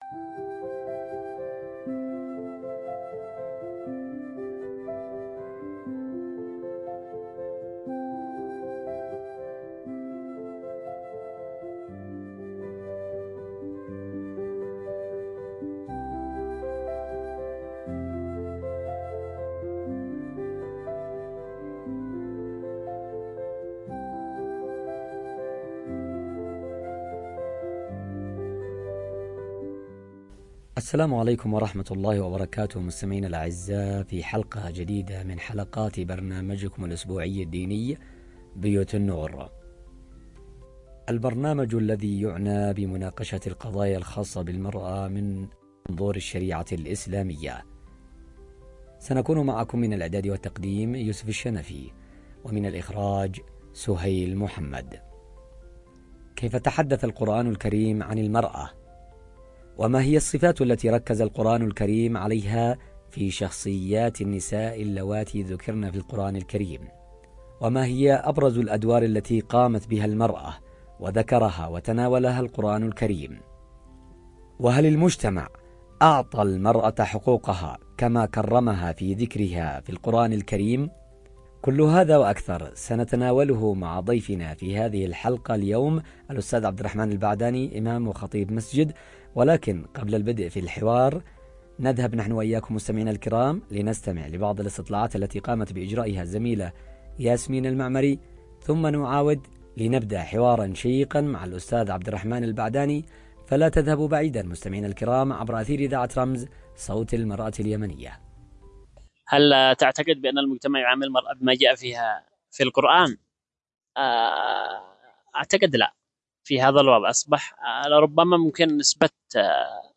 نقاش قرآني عميق
📅 الجمعة ⏰ الساعة 10:00 صباحاً 📻 عبر أثير إذاعة رمز 📌 لا تفوّتوا هذه الحلقة الملهمة التي تجمع بين روح النص القرآني وواقع المرأة في مجتمعاتنا!